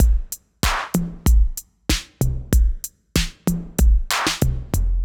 Index of /musicradar/80s-heat-samples/95bpm